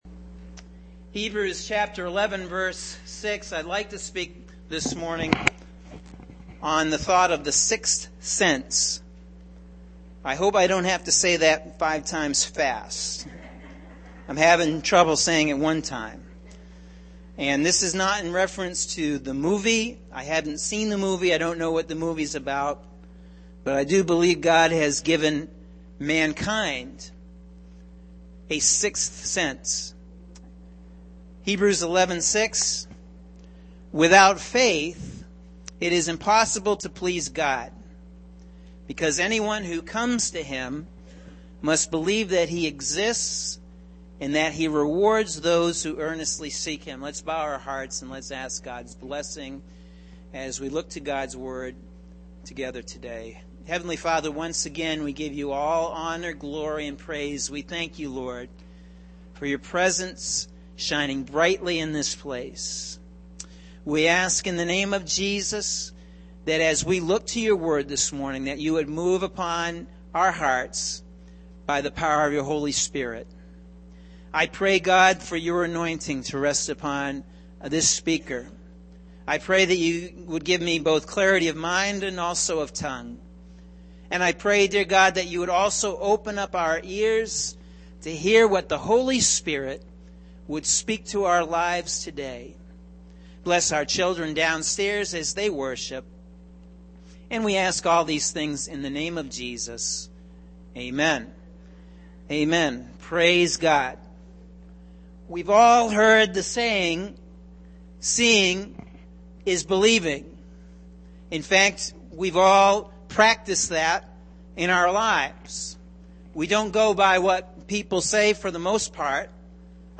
Sunday May 29th – AM Sermon – Norwich Assembly of God